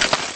PixelPerfectionCE/assets/minecraft/sounds/mob/stray/step1.ogg at mc116